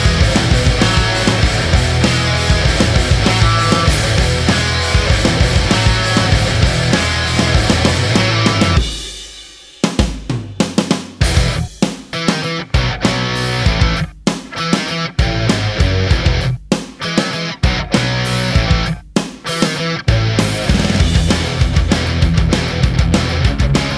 no Backing Vocals Indie / Alternative 2:59 Buy £1.50